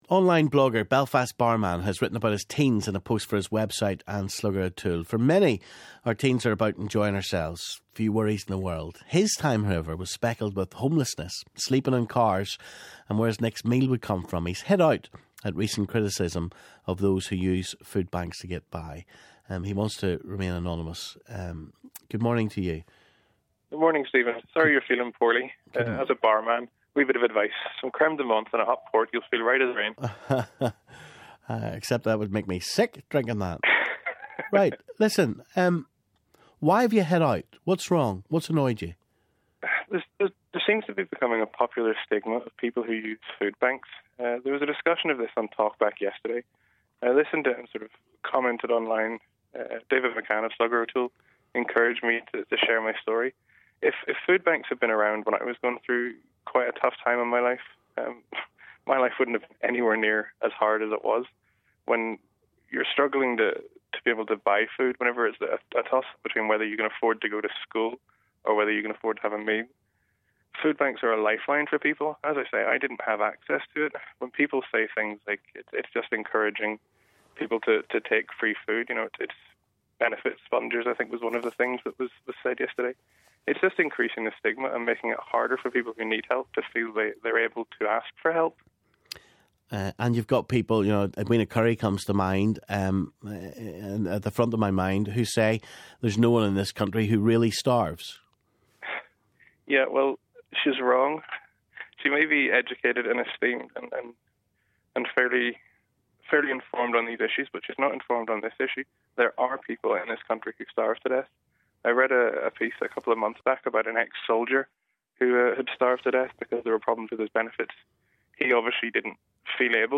He has hit out at recent criticism of those who use foodbanks to get by. He shares his story with Stephen